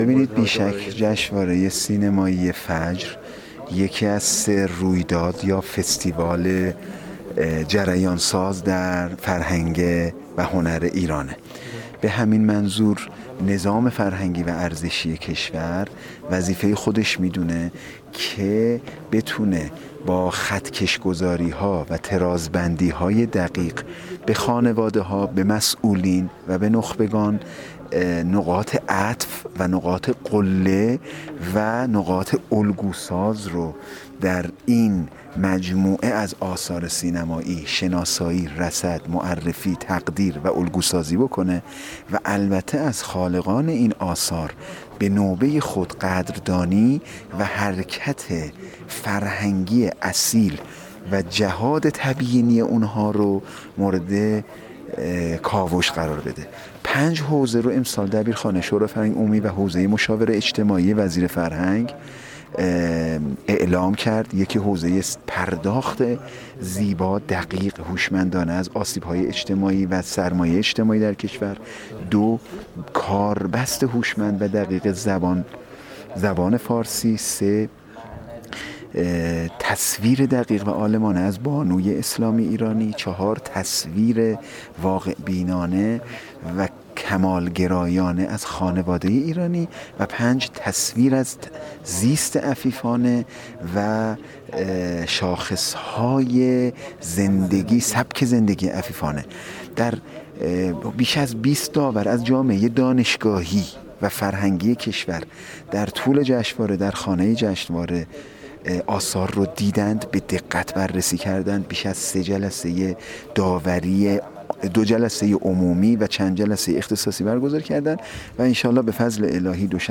در همین راستا با سیدمجید امامی، دبیر شورای فرهنگ عمومی کشور و مشاور وزیر فرهنگ و ارشاد اسلامی در گفت‌وگو با ایکنا به تبیین ضرورت بهره‌گیری از فرصت جشنواره فیلم فجر برای الگوسازی فرهنگی و اجتماعی پرداخت و اظهار کرد: بی‌شک جشنواره سینمایی فجر یکی از ۳ رویداد یا فستیوال جریان‌ساز در فرهنگ و هنر ایران است.